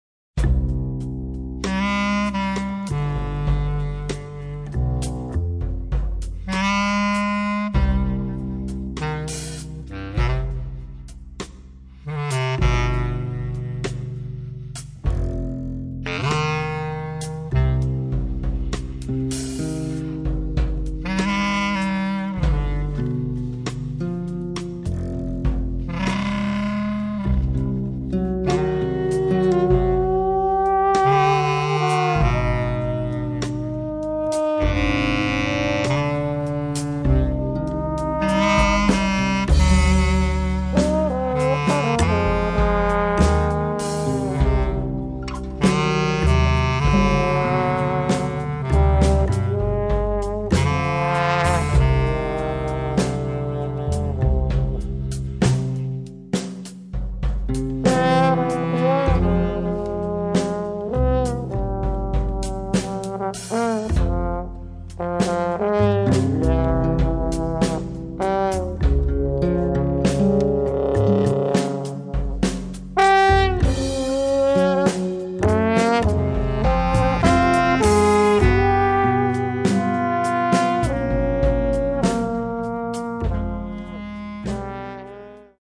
double bass, acoustic bass guitar
bass clarinet
trombone
drums
un omaggio pensoso e delicato
un malinconico e "ombreggiato" accompagnamento